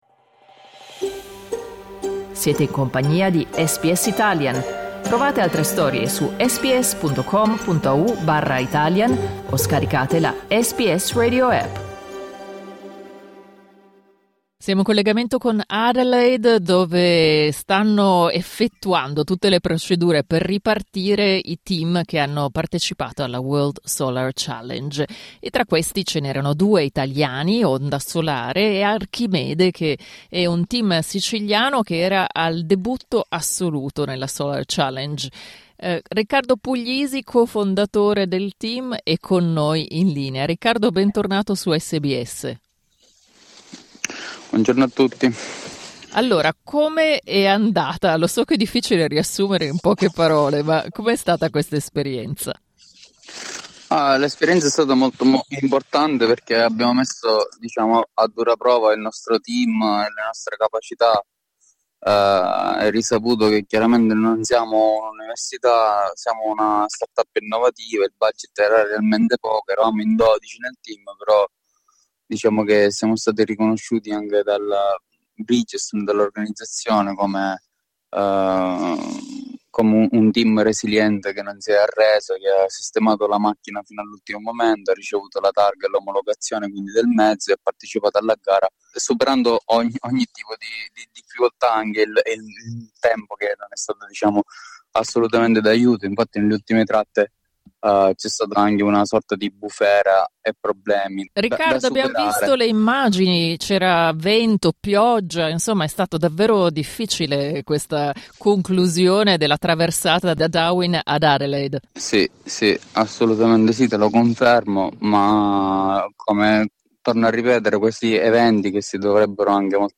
Clicca sul tasto "play" in alto per ascoltare l'intervista Il team Archimede all'arrivo ad Adelaide.